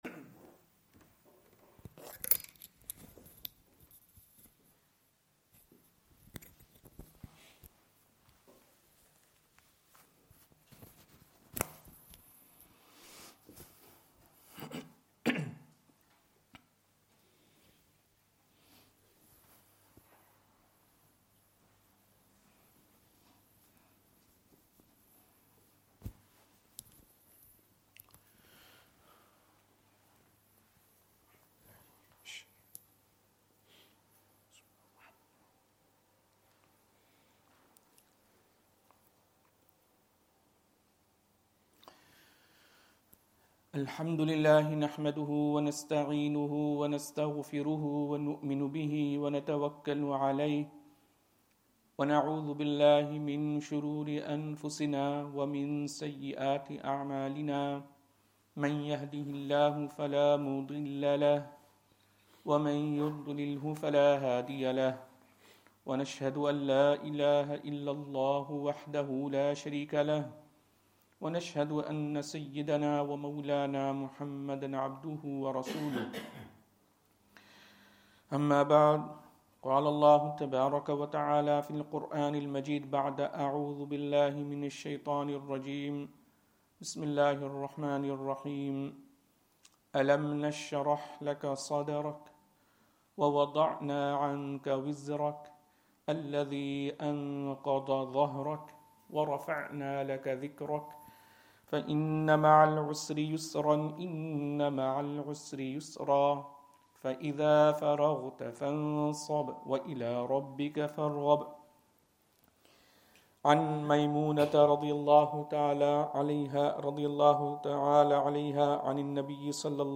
Madni Masjid, Langside Road, Glasgow